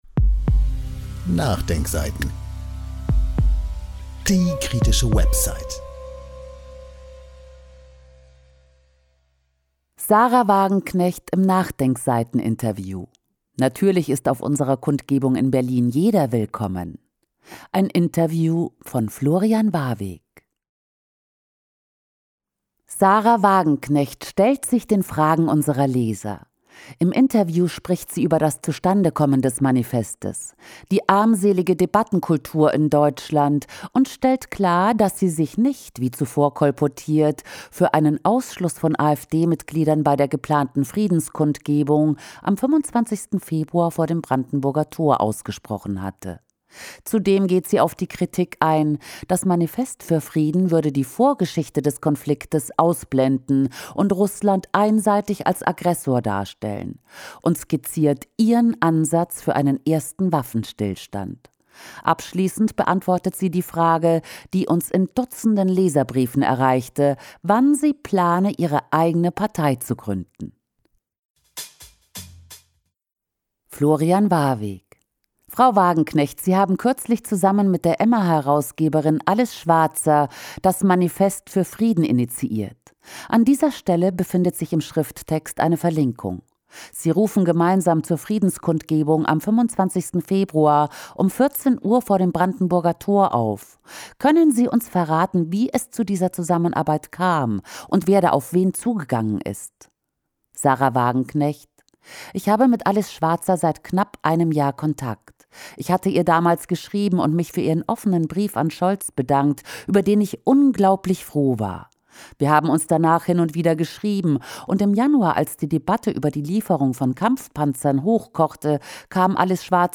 Sahra Wagenknecht stellt sich den Fragen unserer Leser. Im Interview spricht sie über das Zustandekommen des Manifests, die „armselige Debattenkultur“ in Deutschland und stellt klar, dass sie sich nicht, wie zuvor kolportiert, für einen Ausschluss von AfD-Mitgliedern bei der geplanten Friedenskundgebung am 25. Februar vor dem Brandenburger Tor ausgesprochen hatte.